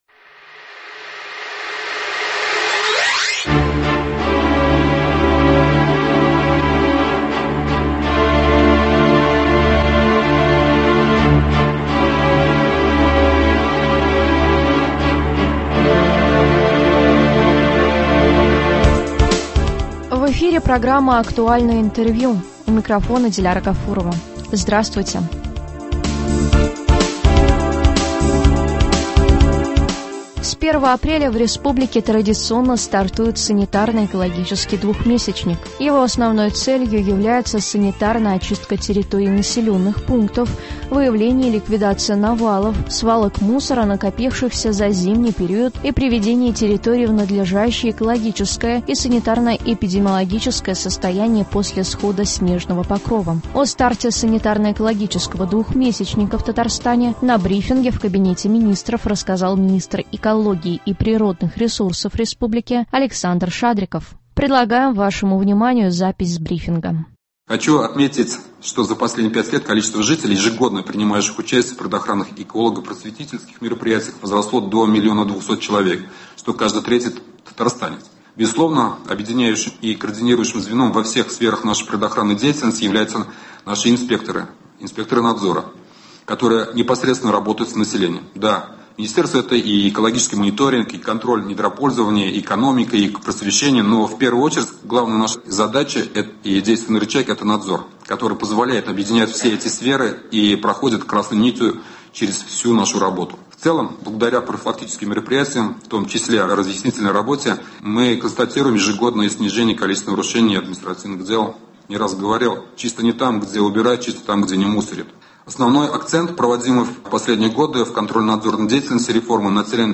Актуальное интервью (29.03.23)